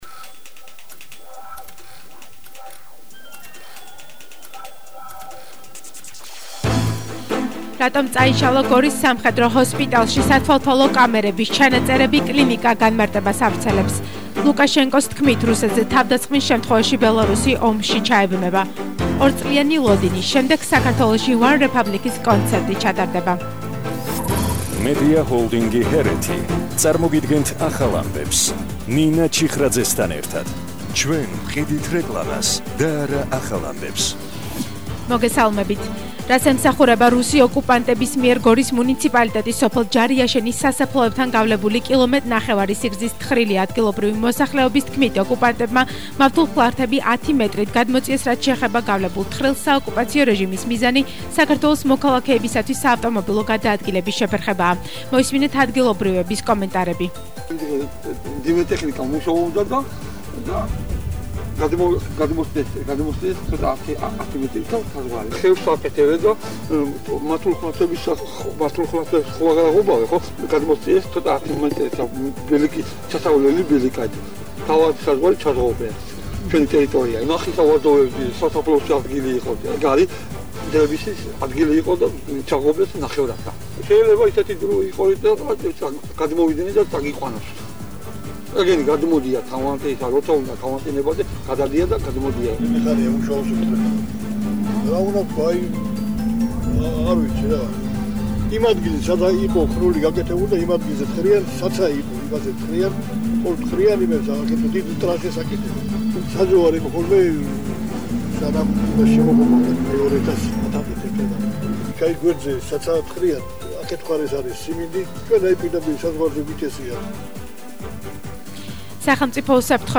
ახალი ამბები 16:00 საათზე – 28/01/22 - HeretiFM